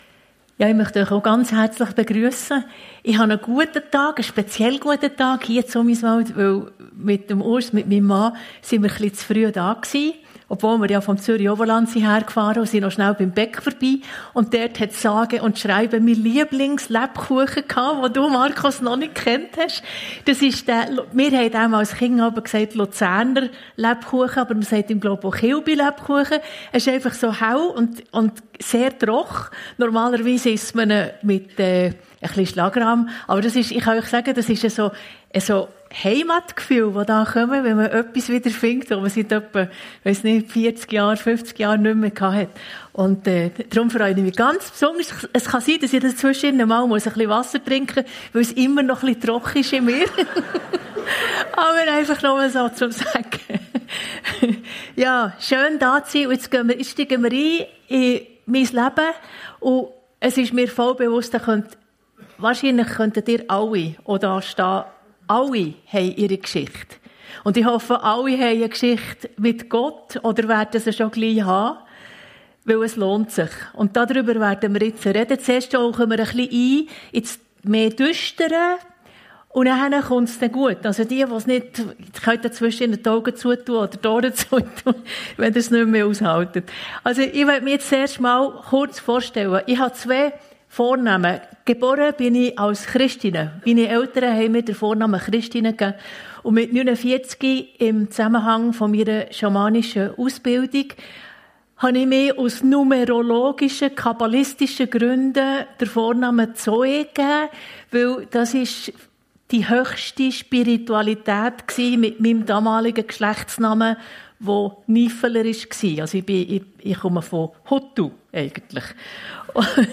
Talkgottesdienst